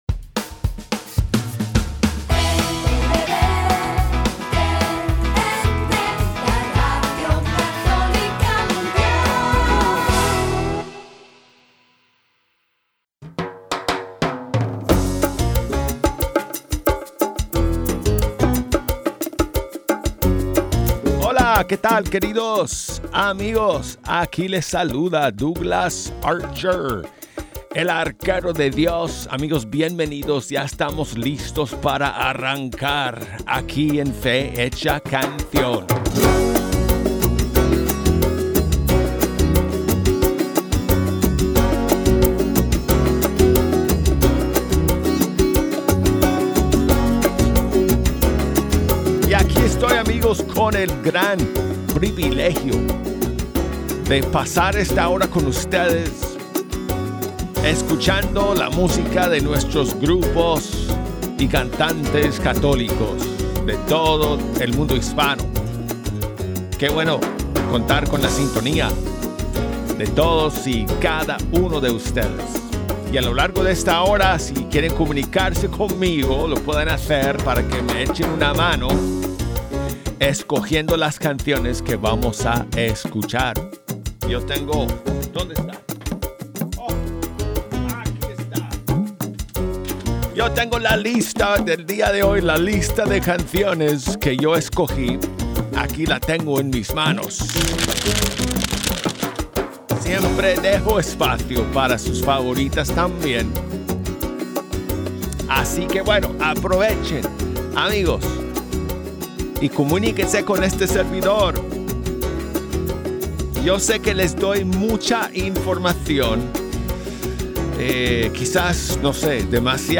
Fe hecha canción es el programa de EWTN Radio Católica Mundial que promociona la música de los grupos y cantantes católicos del mundo hispano. Desde el Estudio 3 de Radio Católica Mundial